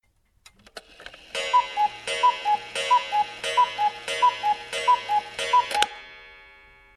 klingelton-kuckucksuhr.mp3